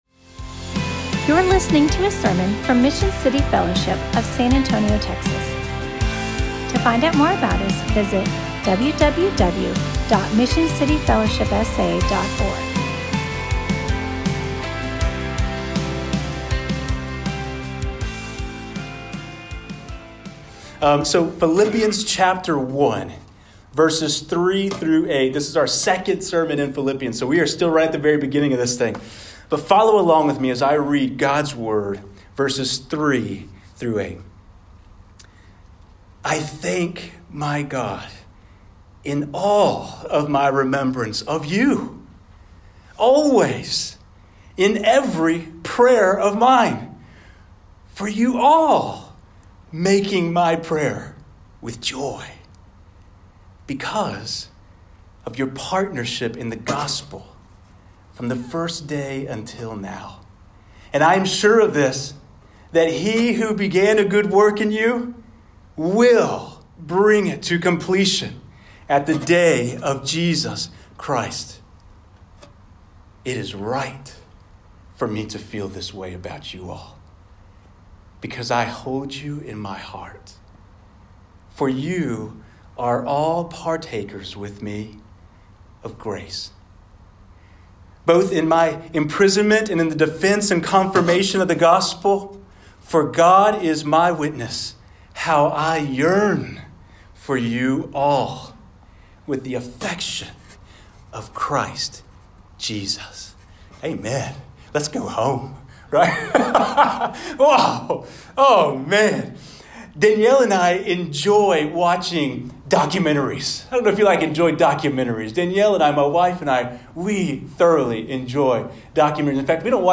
MCF-Sermon-phil-1-3-8.mp3